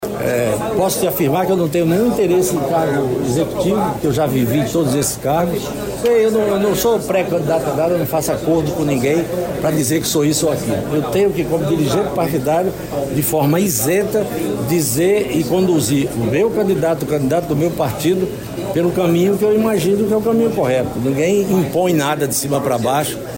A fala ocorreu após o ato de lançamento da pré-candidatura de Alberto Neto a Prefeitura de Manaus, na sede do PL, nesta sexta-feira, 22. O político, que já foi prefeito de Manaus e acumulou passagens pelo legislativo e executivo estadual e federal, descartou a possibilidade de concorrer a algum cargo político nas eleições de 2024.